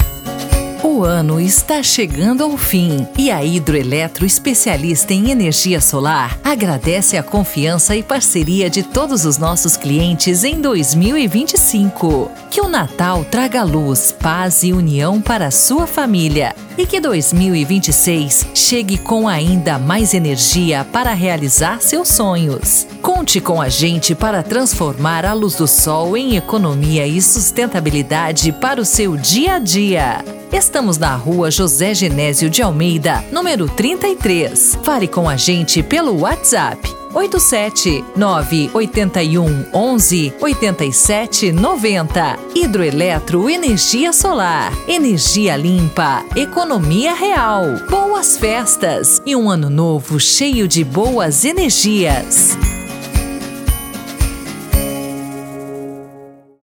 Gravamos esse semana passada. porém, houve uma allteração. vou enviar o spot pronto para ouvir a entonação que foi gravada.